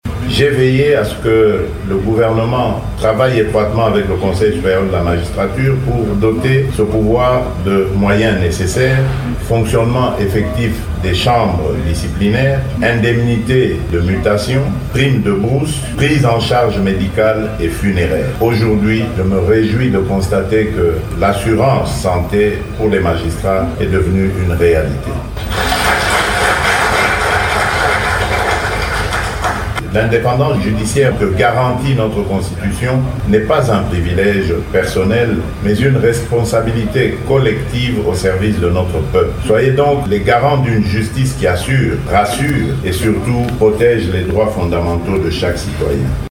Il a formulé cette recommandation lors de l’ouverture des travaux de la session ordinaire du Conseil supérieur de la magistrature (CSM), à Kinshasa.
Vous pouvez écouter les propos du Chef de l'Etat: